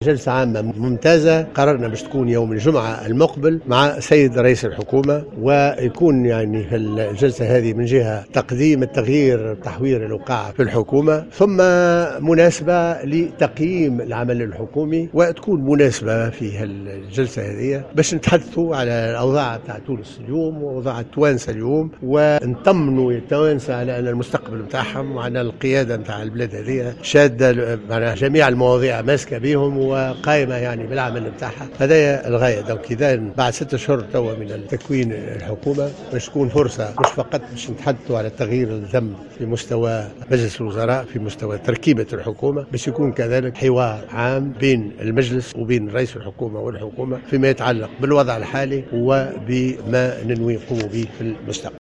ّأكد رئيس مجلس نواب الشعب محمد الناصر في تصريح لمراسل الجوهرة اف ام، أن جلسة عامة ستلتئم يوم الجمعة 17 مارس 2017 للتصويت على منح الثقة لعضوي الحكومة الجديدين والتطرق إلى التحوير الوزاري الأخير، بحضور رئيس الحكومة يوسف الشاهد.